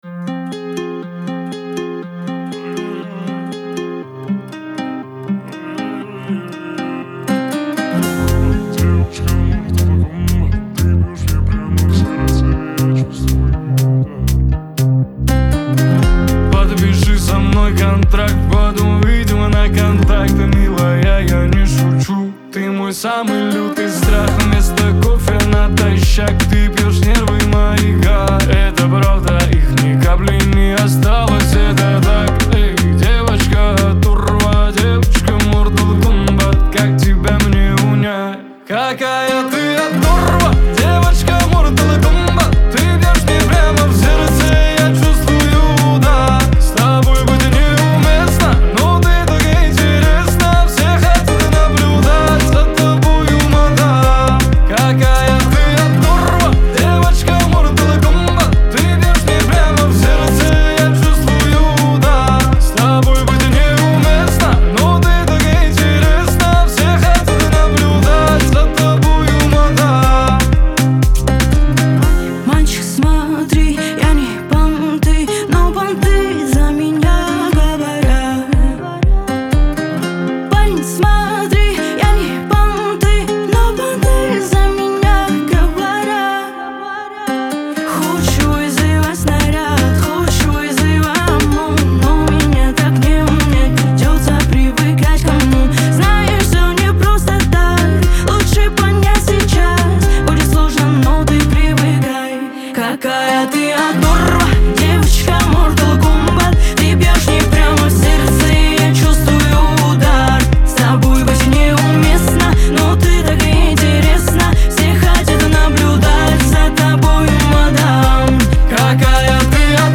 Лирика , Кавказ – поп , грусть